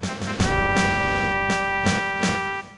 Trumpet.wav